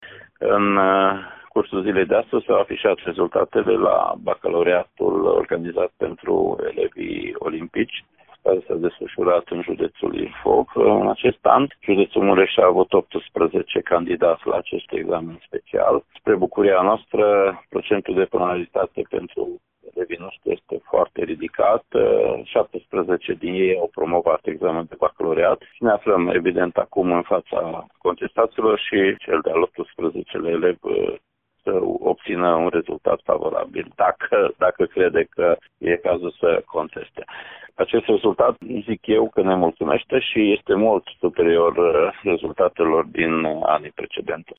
Inspectorul școlar general al județului Mureș, Ștefan Someșan.